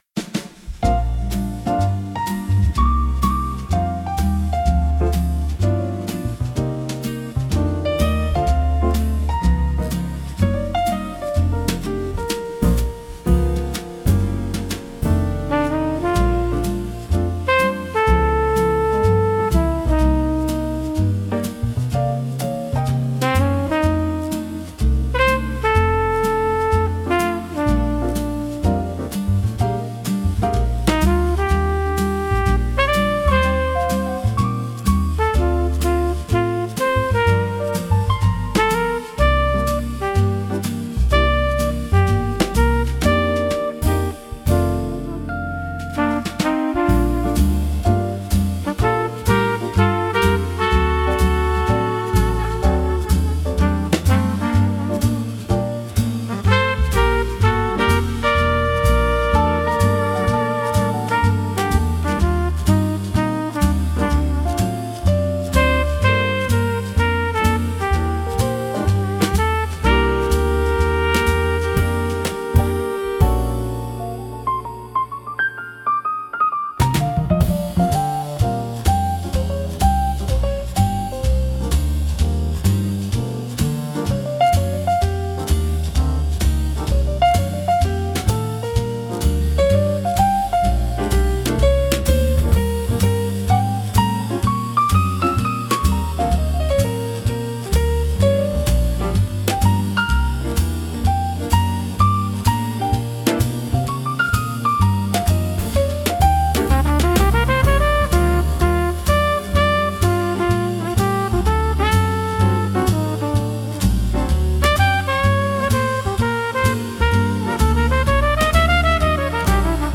instrumental 8